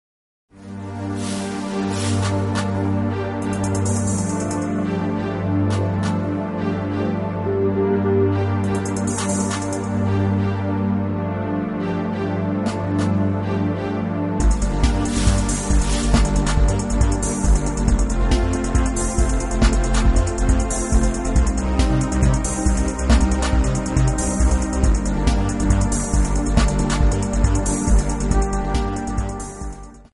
INSTRUMENTAL
Pop